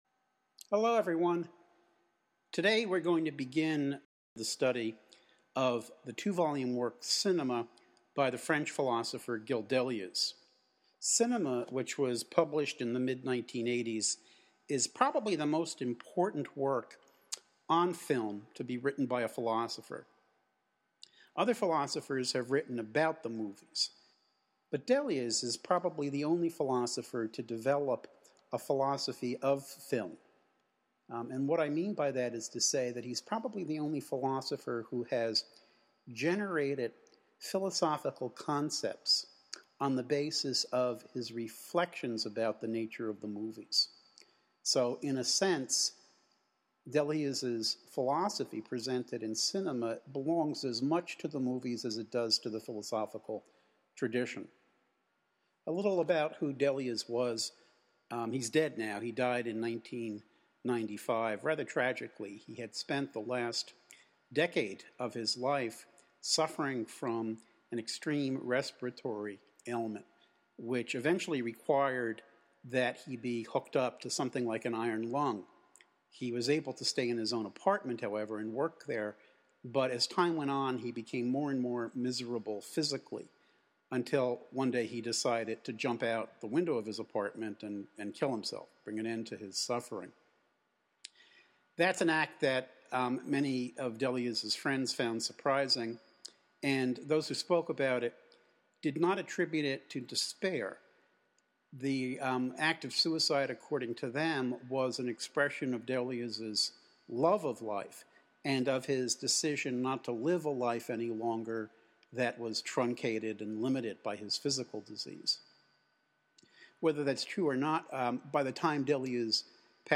Lecture: Podcast for Session 4